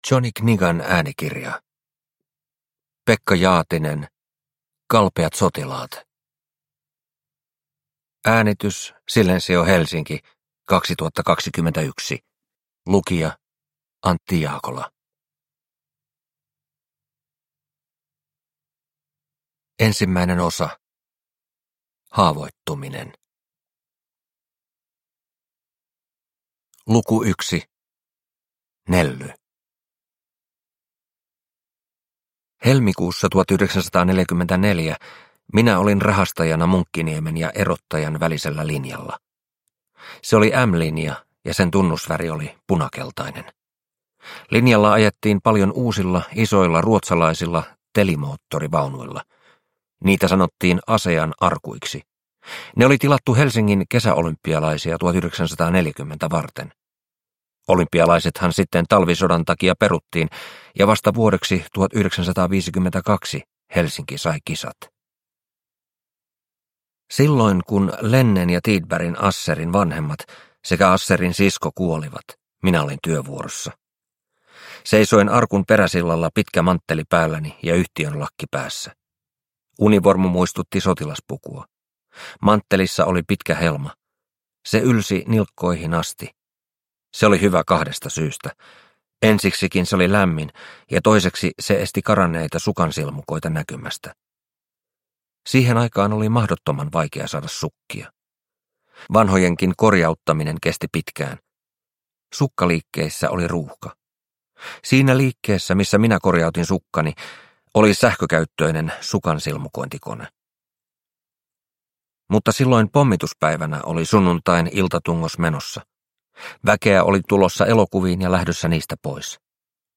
Kalpeat sotilaat – Ljudbok